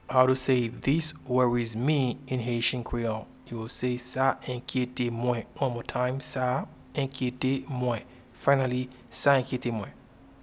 Pronunciation and Transcript:
This-worries-me-in-Haitian-Creole-Sa-enkyete-mwen.mp3